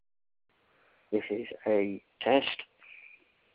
Ok not inspiring, but just testing out the new phoneboo number